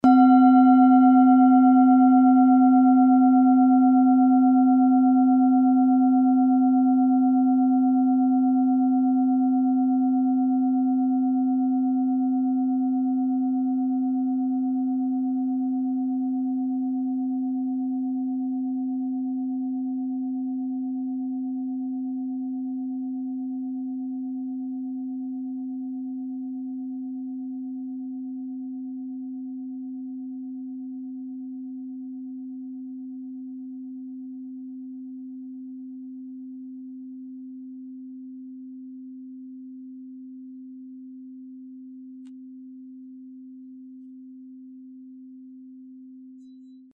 Klangschale Bengalen Nr.4
Klangschale-Gewicht: 810g
Klangschale-Durchmesser: 16,5cm
Sie ist neu und wurde gezielt nach altem 7-Metalle-Rezept in Handarbeit gezogen und gehämmert.